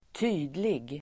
Uttal: [²t'y:dlig]